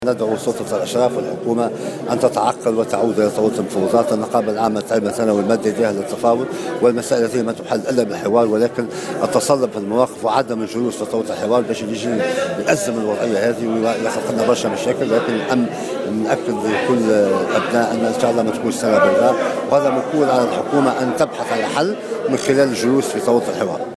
وأضاف المباركي في تصريح لمراسلة الجوهرة اف ام على هامش زيارة أداها إلى سوسة اليوم السبت في إطار إحياء "يوم الأرض"، أنه يجب على الحكومة أن تتعقّل وتعود إلى طاولة الحوار لأن التصلب في المواقف وعدم العودة إلى الحوار سيعمق الأزمة، وفق تعبيره.